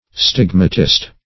Stigmatist \Stig"ma*tist\, n.